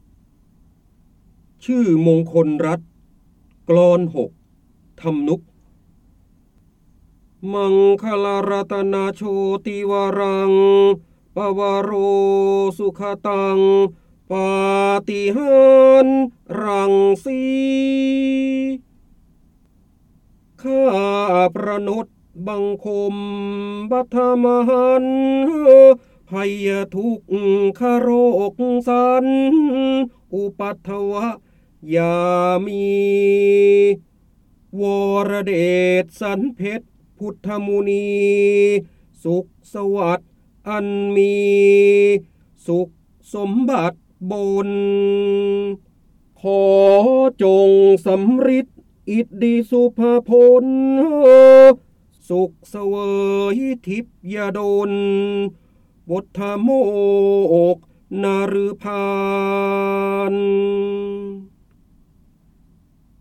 เสียงบรรยายจากหนังสือ จินดามณี (พระโหราธิบดี) ชื่อมงคลรัตน กลอน ๖ ทำนุกฯ
คำสำคัญ : ร้อยแก้ว, ร้อยกรอง, การอ่านออกเสียง, จินดามณี, พระเจ้าบรมโกศ, พระโหราธิบดี
ลักษณะของสื่อ :   คลิปการเรียนรู้, คลิปเสียง